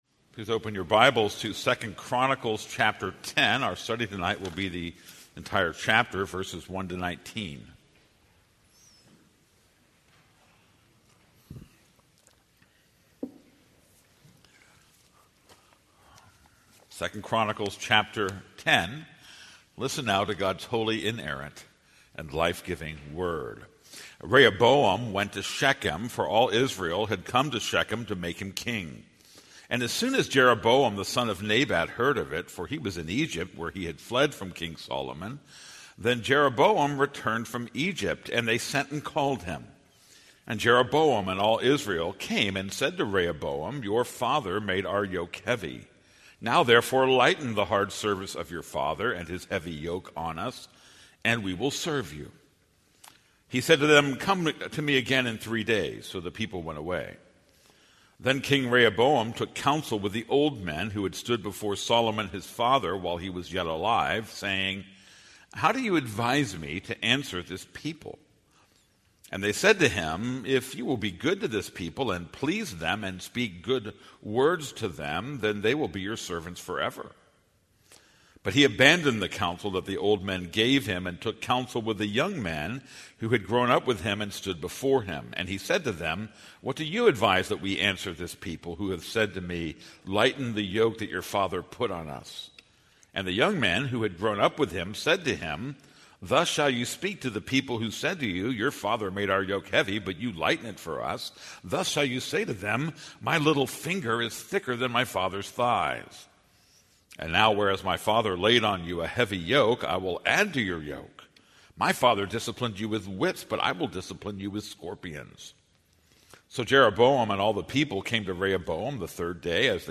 This is a sermon on 2 Chronicles 10:1-19.